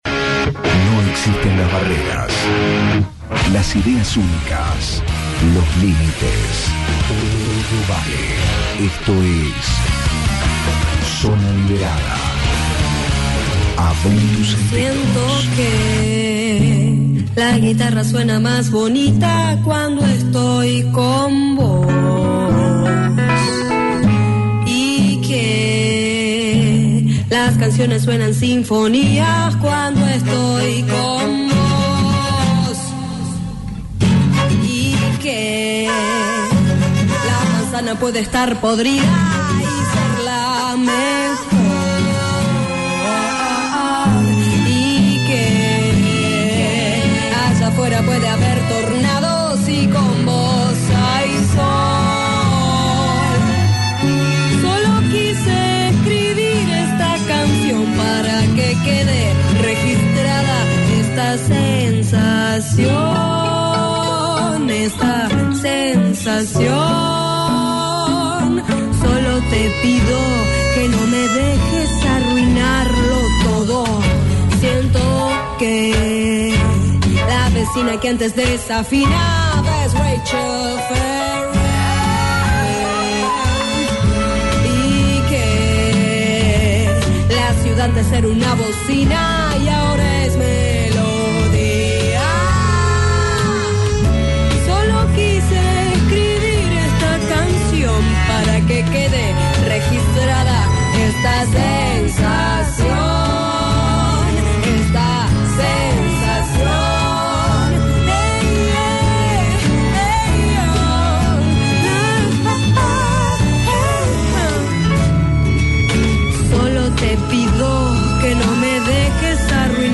entrevista y acústico
Ya podés escuchar la entrevista y el acústico que nos regaló.